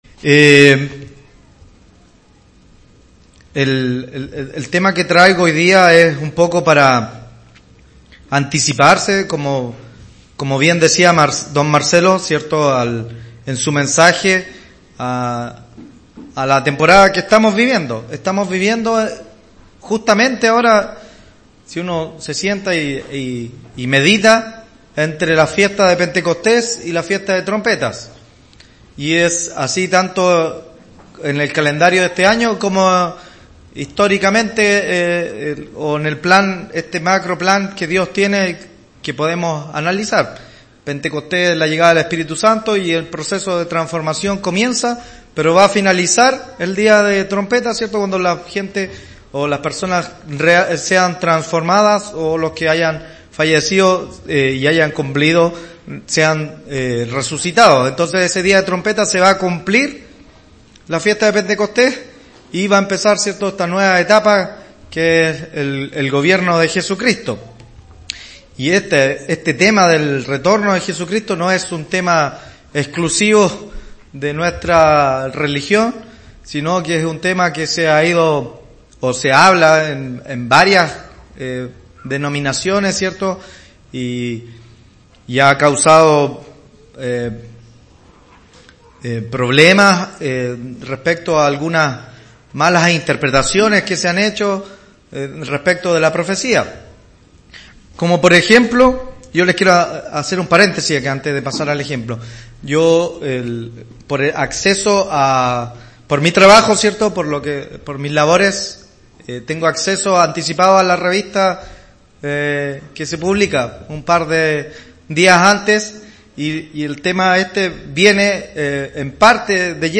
Sermones
Given in Santiago